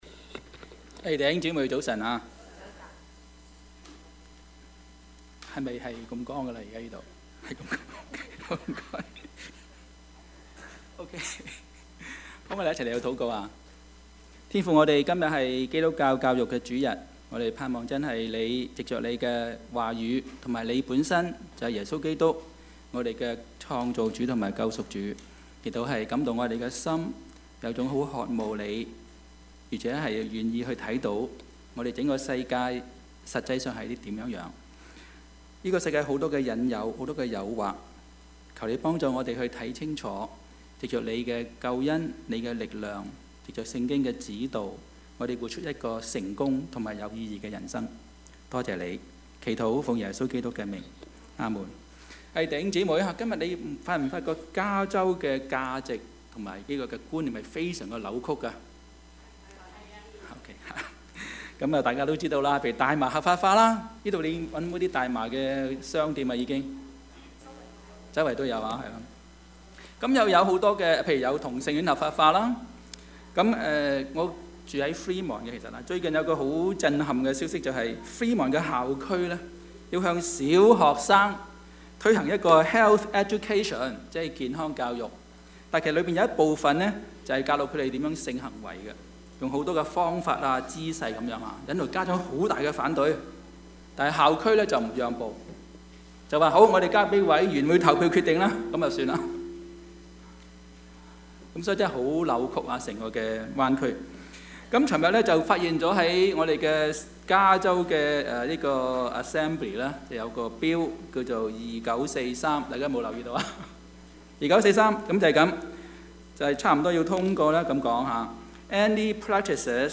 Service Type: 主日崇拜
Topics: 主日證道 « 立定心志 恩 »